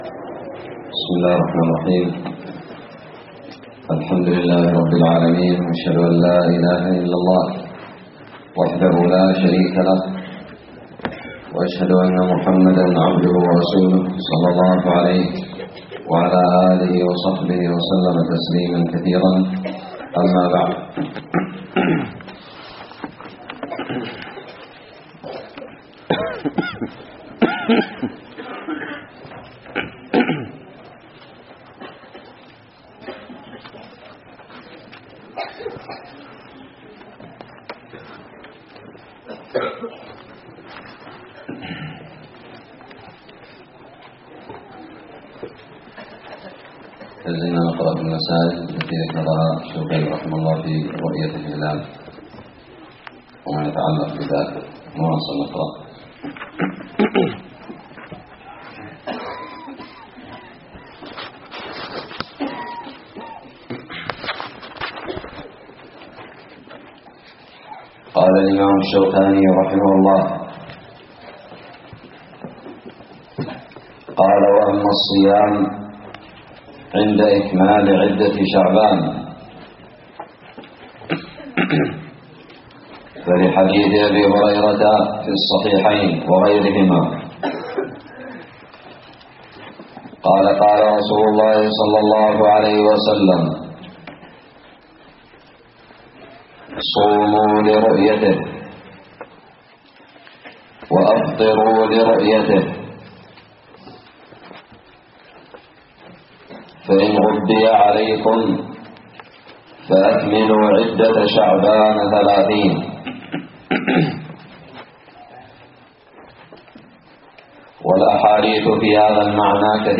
الدرس السادس من كتاب الصيام من الدراري
ألقيت بدار الحديث السلفية للعلوم الشرعية بالضالع